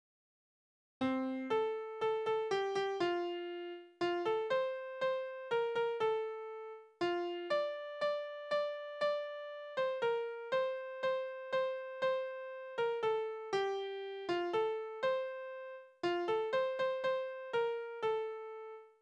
Soldatenlieder: Ausmarsch 1866
Tonart: F-Dur
Taktart: 3/4
Tonumfang: Oktave
Besetzung: vokal